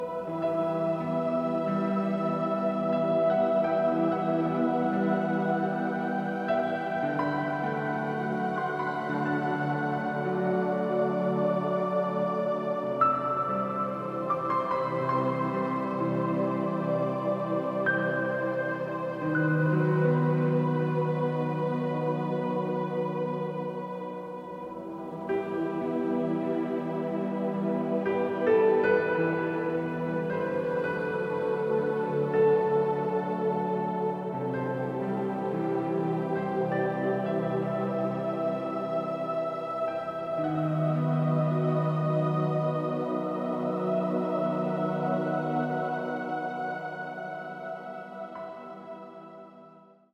Music to Enhance Your own personal Home Spa Experience